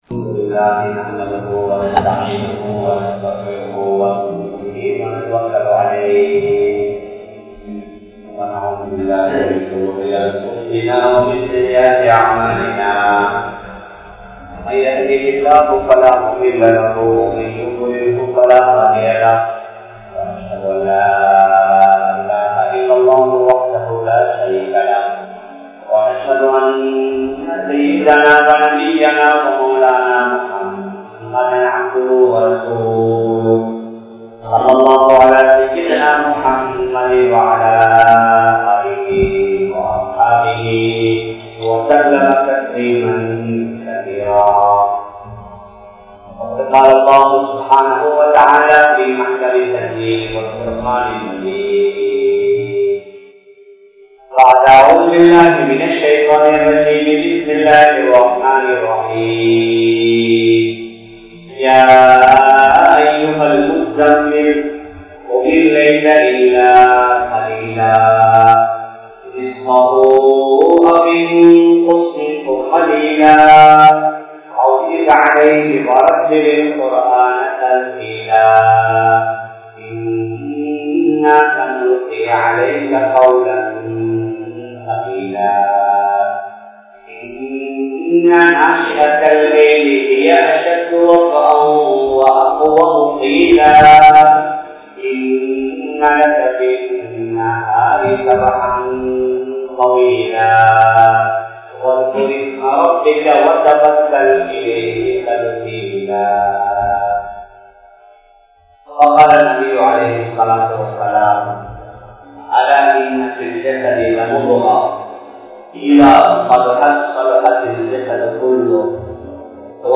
Dhauwath & Ibathath (தஃவத் & இபாதத்) | Audio Bayans | All Ceylon Muslim Youth Community | Addalaichenai
South Eastern University Jumua Masjith